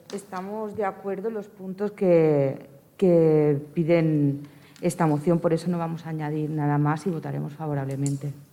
Aquest és un dels acords al qual s’ha arribat al ple de l’Ajuntament de Tordera.
Des de Ciutadans, subscriuen la moció i la regidora Miriam Rocabruna hi votava favorablement: